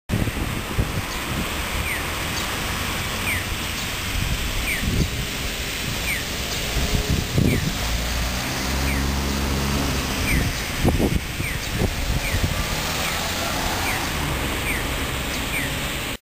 メロディーではないですが、誘導音に小糸の□号がありました。
音自体は、別にほかと変わりはないようですが、青の時間が極端に短いです。
ここも2倍速点滅音付きのようで(^^)。